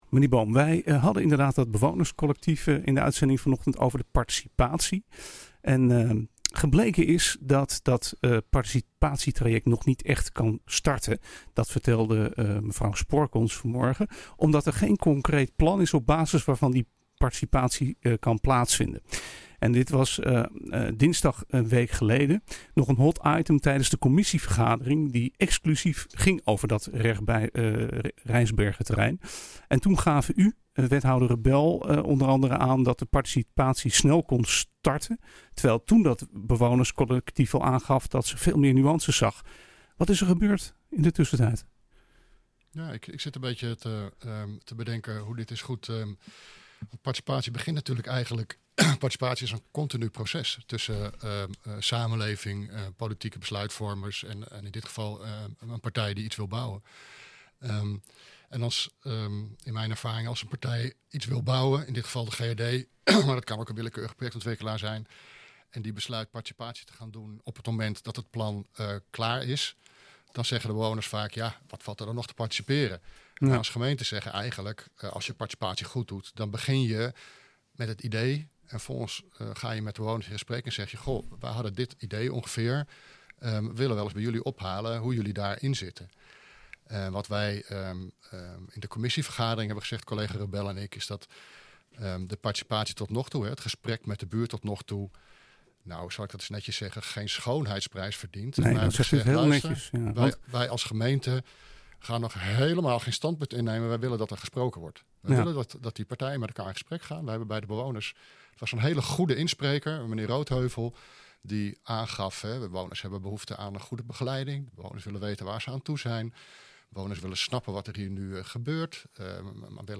Fragment van onze actualiteitenuitzending NH Gooi zaterdag over het Rijsbergenterrein.